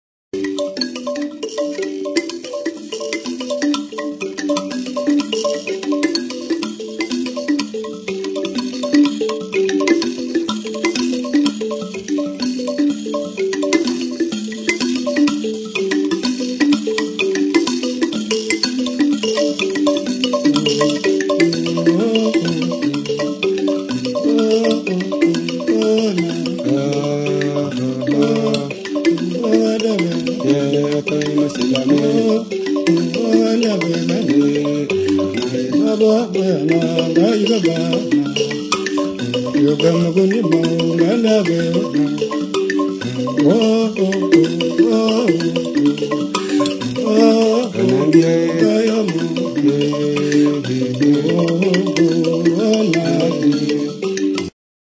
Idiófonos -> Punteados / flexible -> Con caja de resonancia
Languetes pincées (Centroafrique). Instruments de Musique du Monde. Recorded by Simha Arom.
LIKEMBE; SANZA; KALIMBA